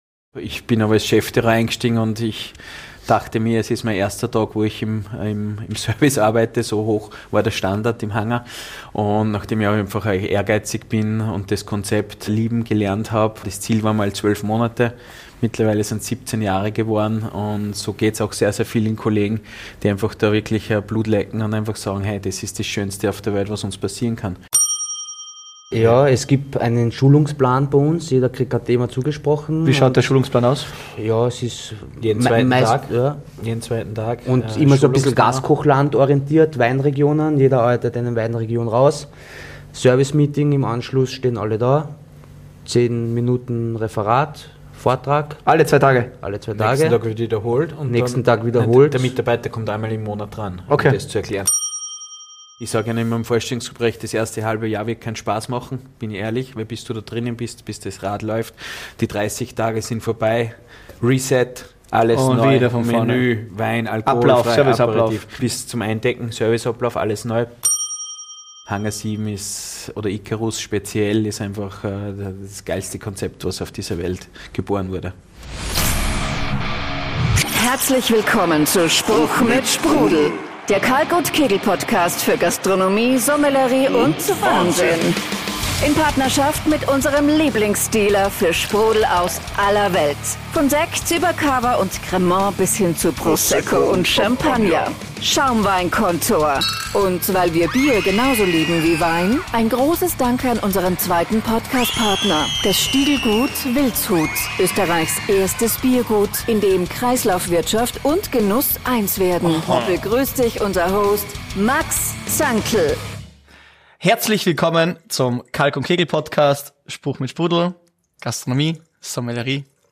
Dieses Mal aufgenommen in der Skybox im Stadion von Red Bull Salzburg.